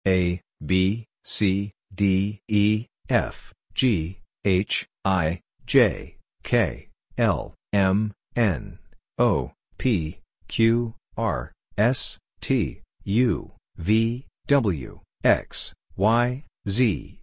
Listen to the pronunciation of each letter and repeat it aloud several times.